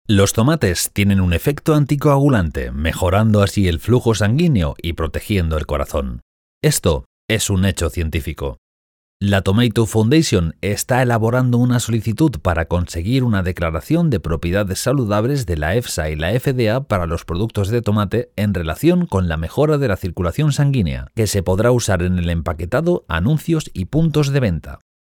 Spanish Castilian male Voice Over (Baritone) Warm and deep voice talent.
Sprechprobe: Industrie (Muttersprache):
Sweet voice, warm, deep, energetic ... very adaptable voice.